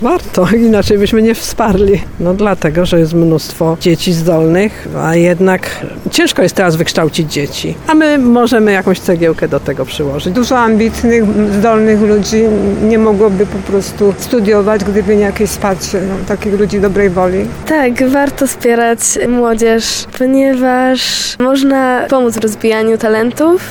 Tarnowianie, z którymi rozmawiała nasza reporterka, zgodnie przyznawali, że ich zdaniem warto wspierać tę inicjatywę.